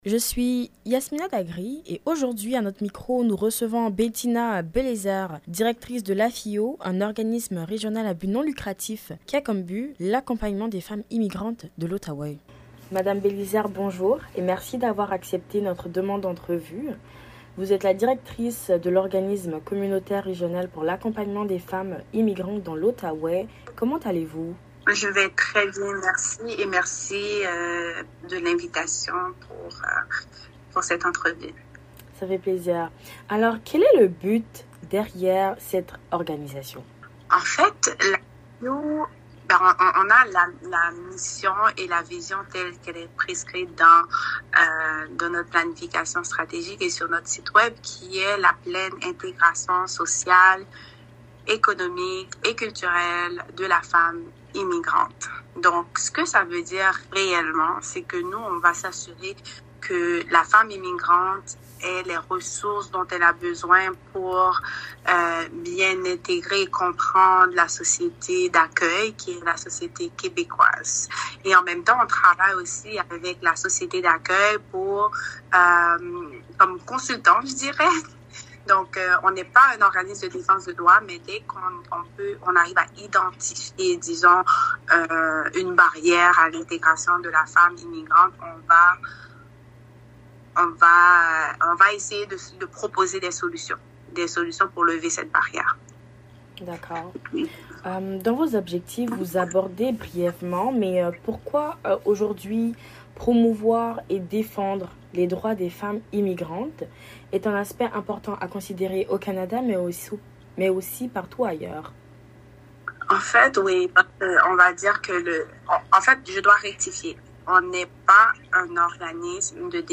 AFIO-ENTREVUE.mp3